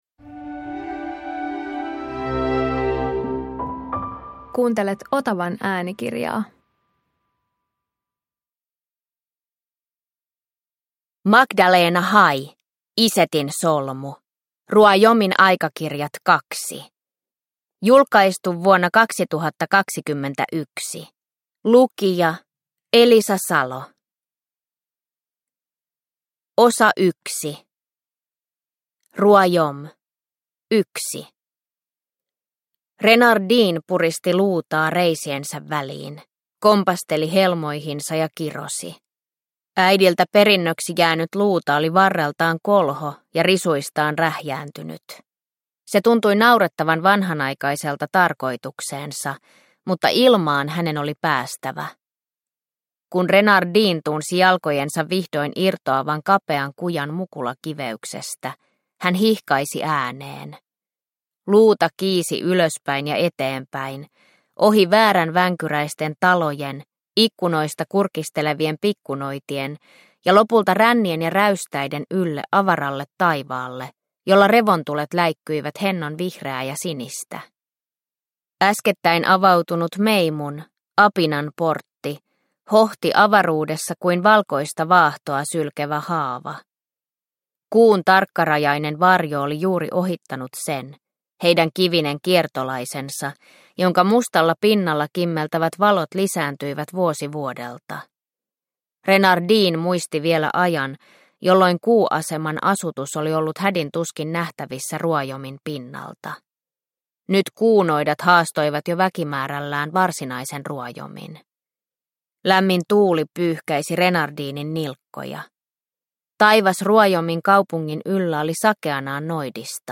Isetin solmu – Ljudbok – Laddas ner